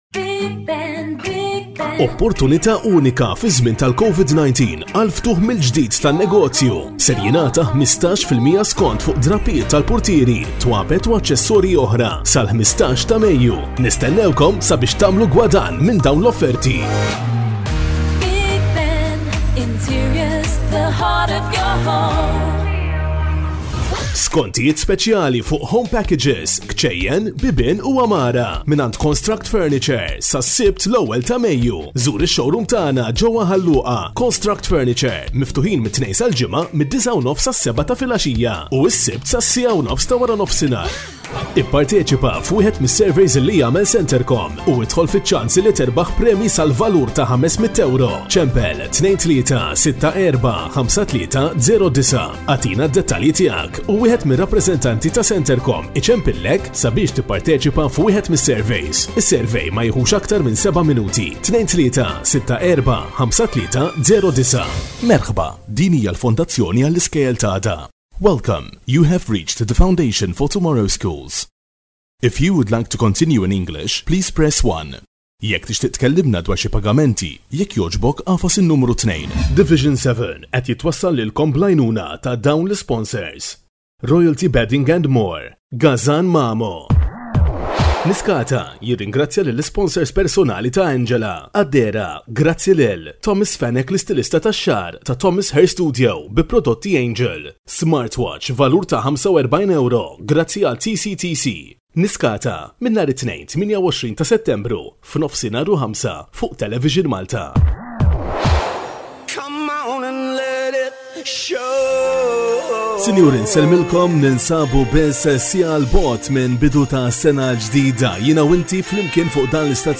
He is a pro voice over that delivers in professional quality.
Corporate